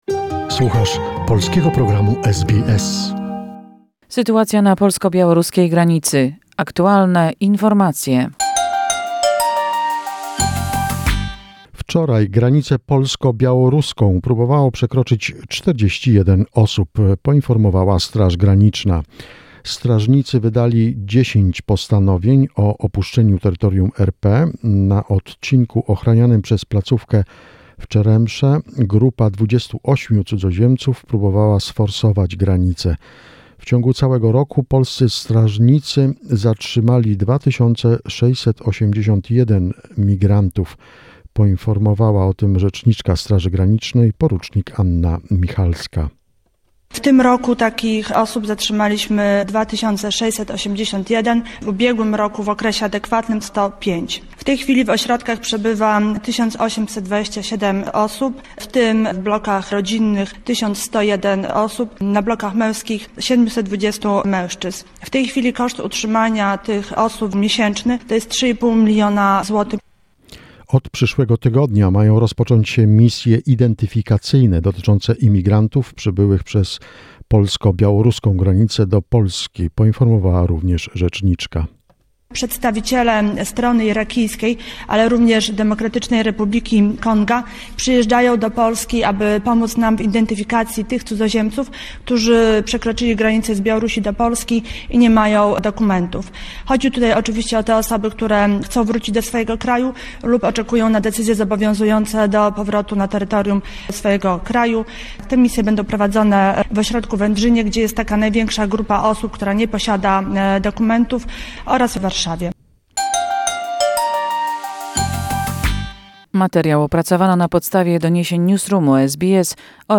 The most recent information and events regarding the Poland-Belarus situation, a short report prepared by SBS Polish.